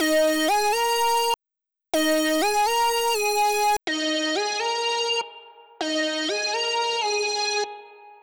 Searing Leads
Try this trick to add grit and presence to any lead, such as this one from Roland Cloud SYSTEM-1
Using Channel-2 on the JC-120, dial in a bit of distortion and engage the Bright button for extra attitude.
JC-120-Lead.wav